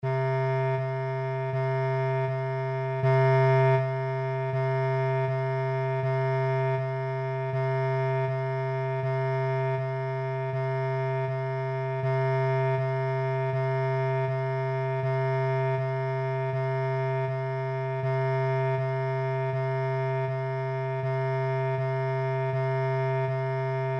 Nota DO
(7x36)+9 = 261